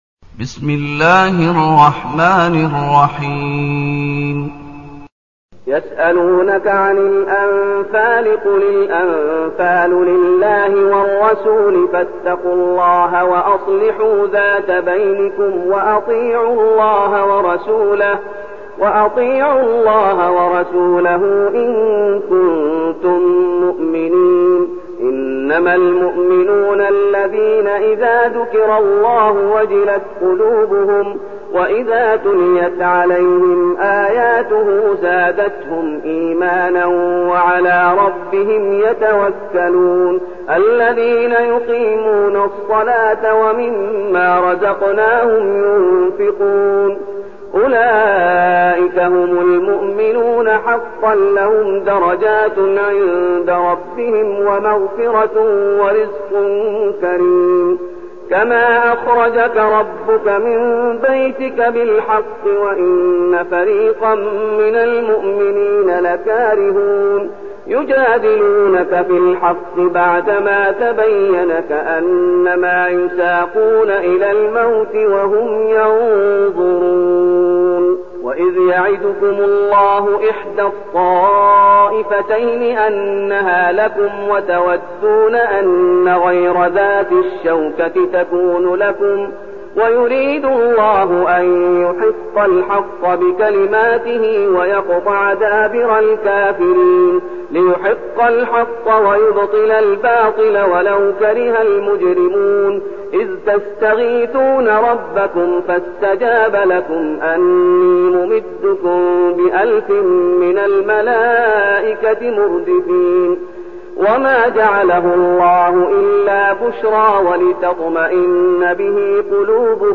المكان: المسجد النبوي الشيخ: فضيلة الشيخ محمد أيوب فضيلة الشيخ محمد أيوب الأنفال